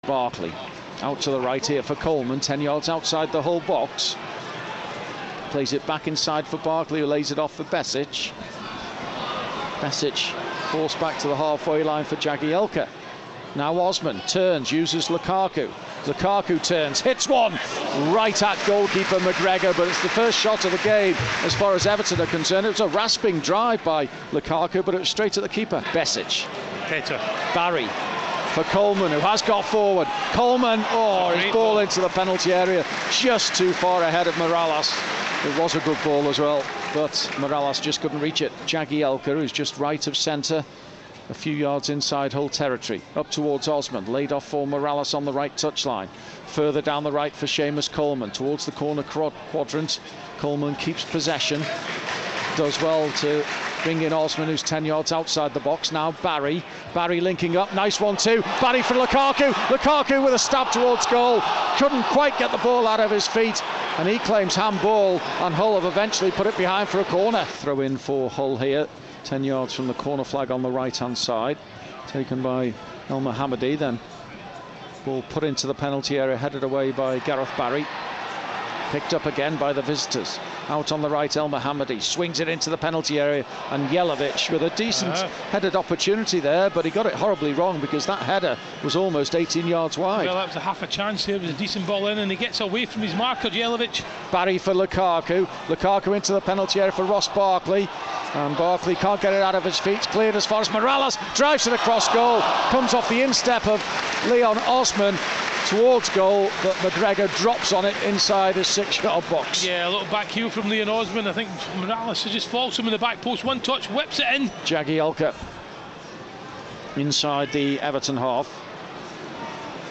Match highlights of the 1-1 draw at Goodison Park